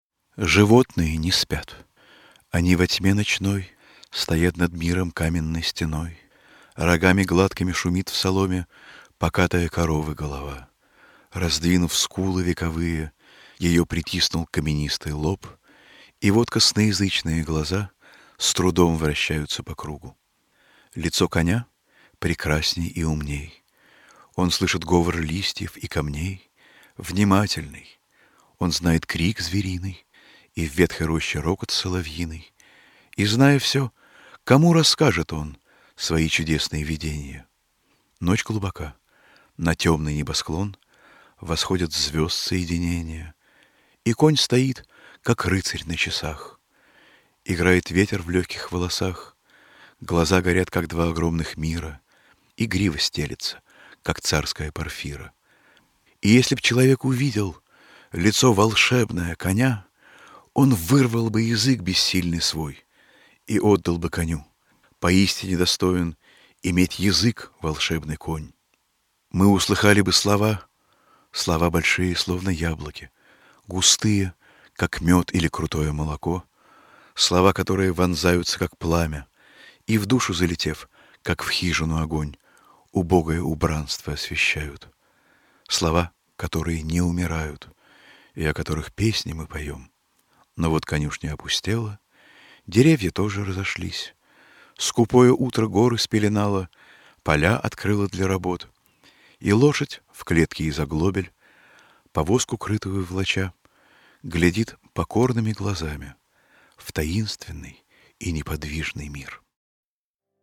2. «Н. Заболоцкий – Лицо коня 1926 (чит. Б. Гребенщиков)» /
Zabolockiy-Lico-konya-1926-chit.-B.-Grebenshhikov-stih-club-ru.mp3